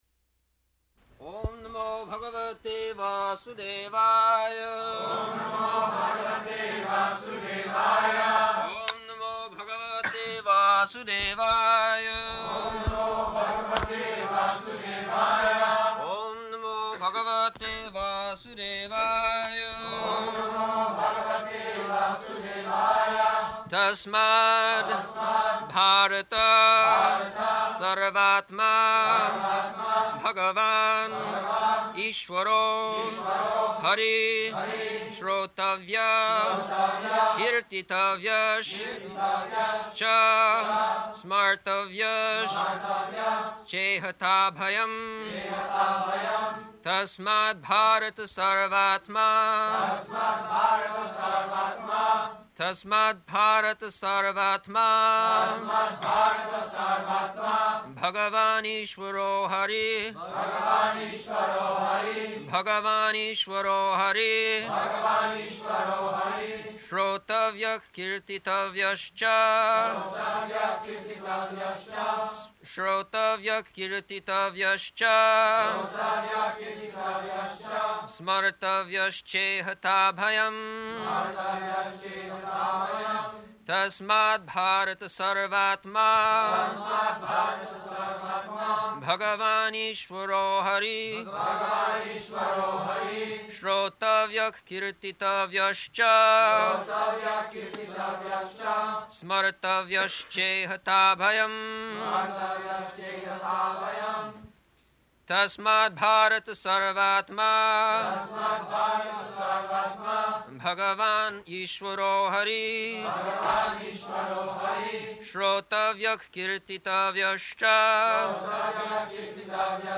June 13th 1974 Location: Paris Audio file
[devotees repeat] [leads chanting of verse, etc.]